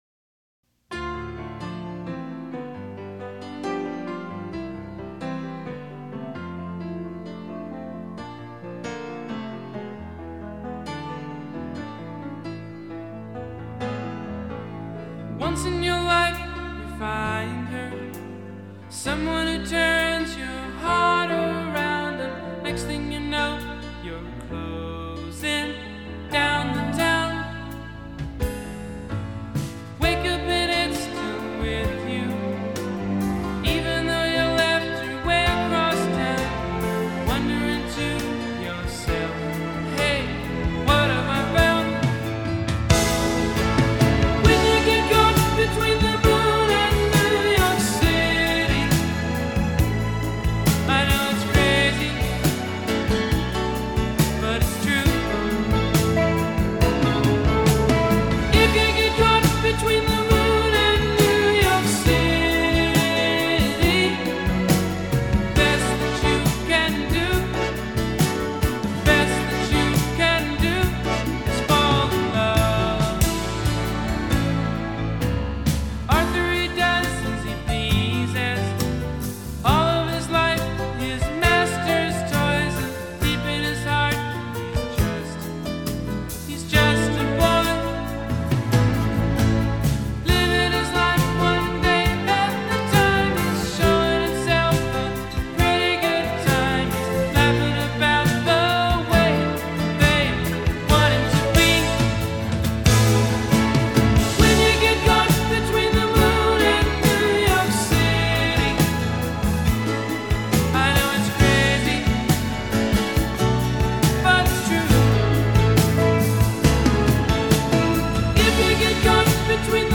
soft rock, or even worse yacht rock.